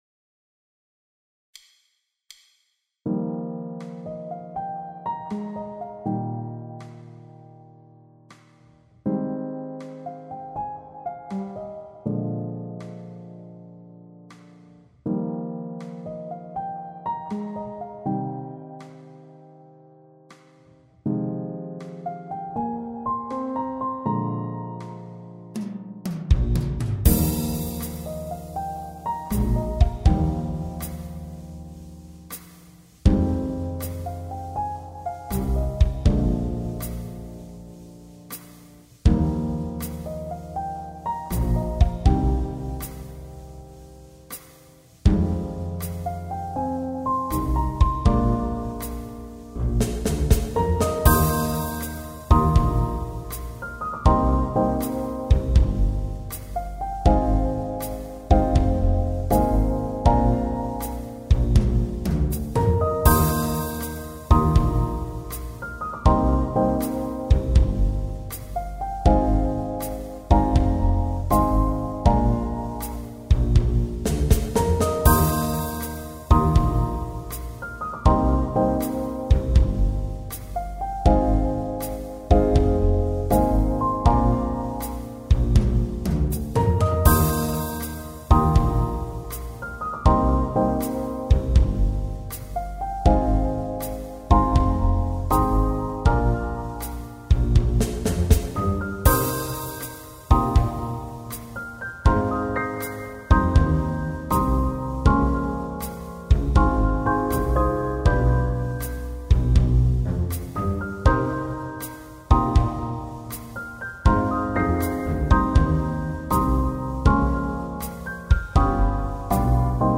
ジャズスローテンポ穏やか